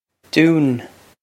dún doon
This is an approximate phonetic pronunciation of the phrase.